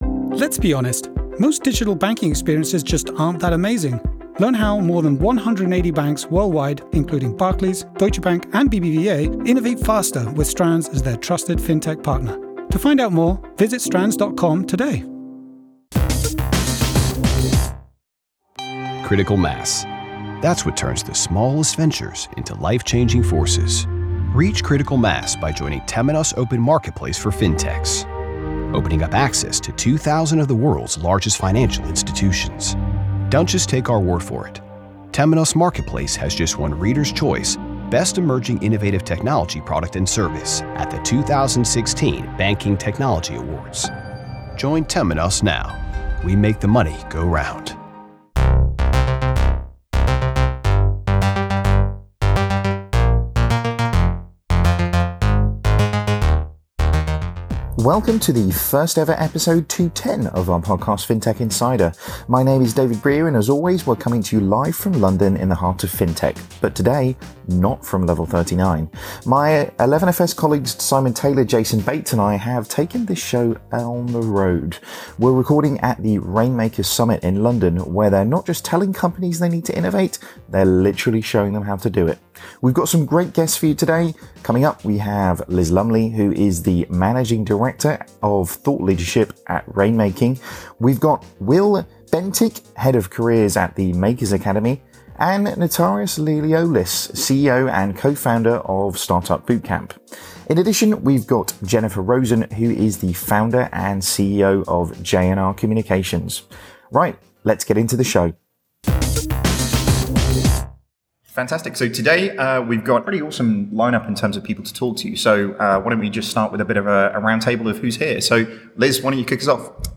We sit down with key participants in London to get the lowdown.